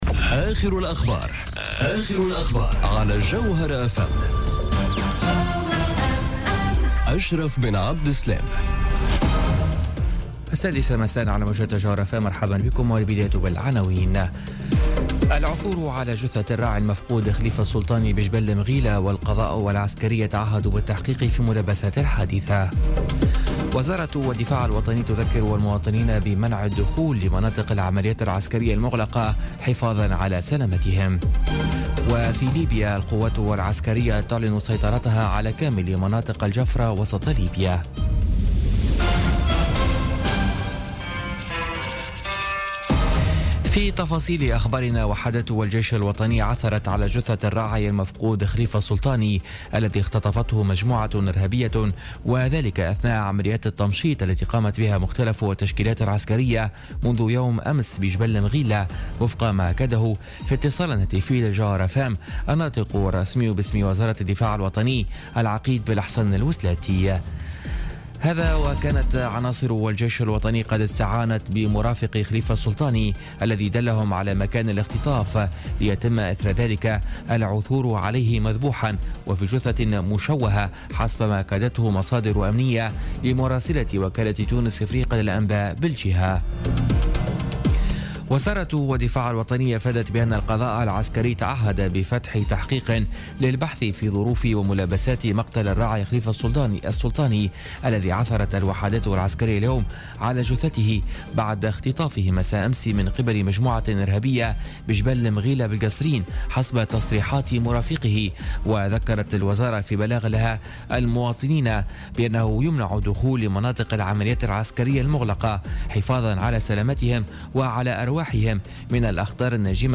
Journal Info 18h00 du samedi 3 Juin 2017